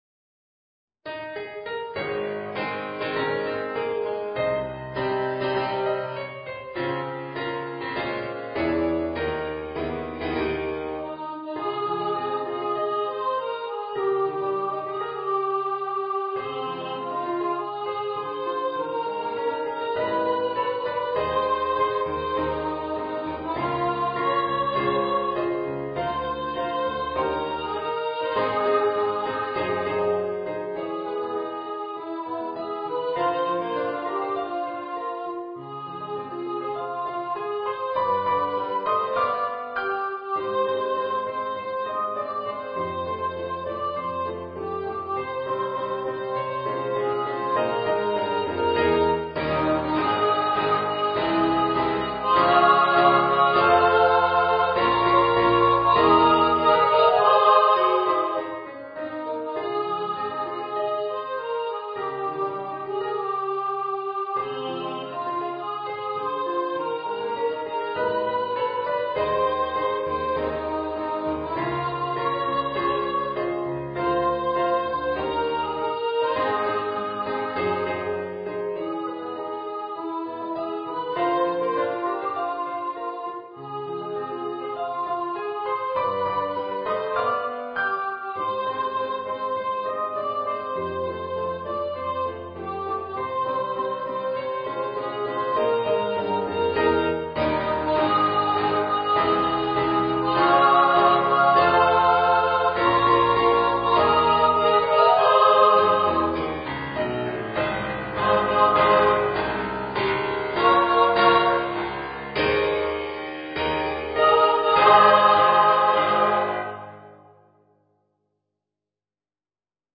先月実施した創立５０周年記念式典で披露した有志生徒による応援歌の合唱の音声を公開いたします。